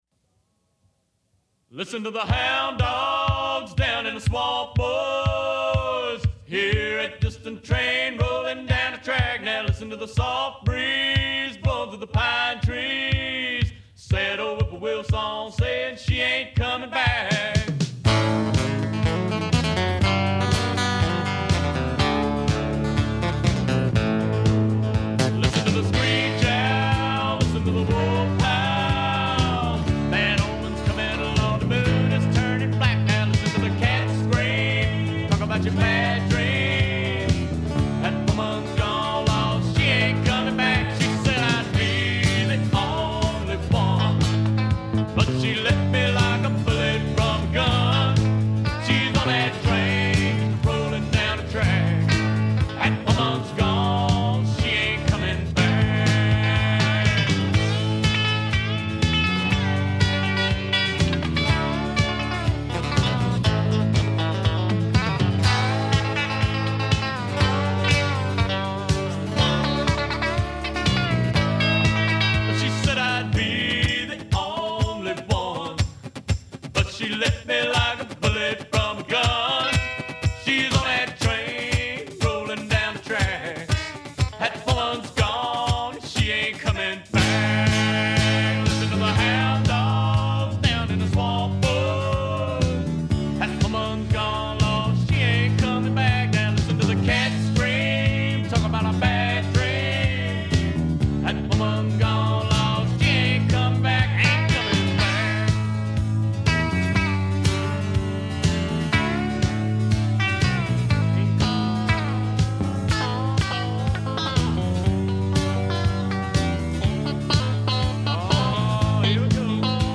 Vocals, Lead guitar
Bass
Keys
Drums